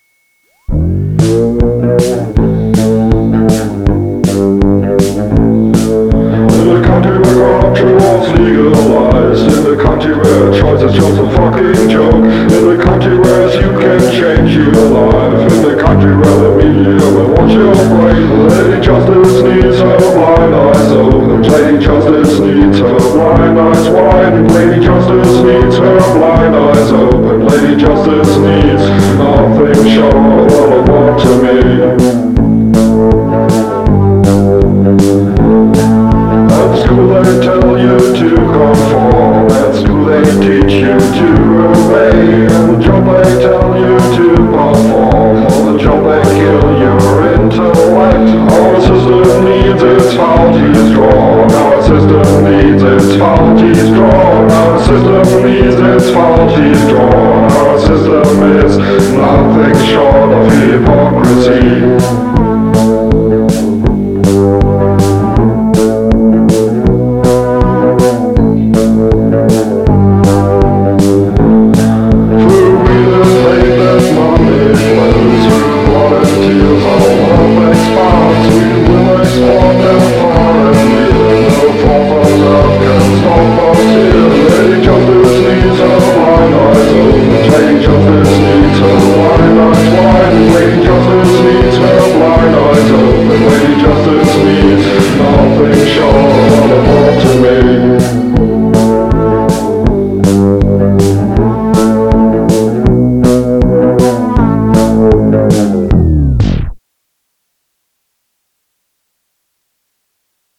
Total fusion in one big confusing package!